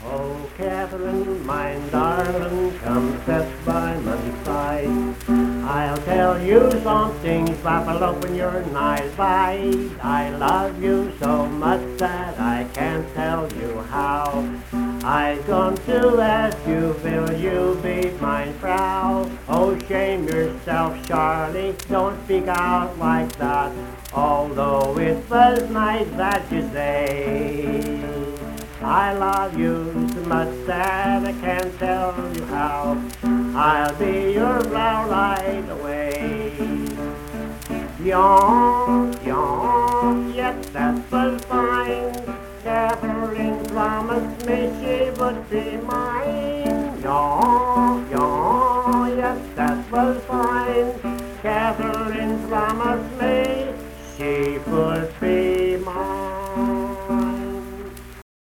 Unaccompanied voice and guitar music
Verse-refrain 1(2). Performed in Hundred, Wetzel County, WV.
Ethnic Songs, Love and Lovers
Voice (sung), Guitar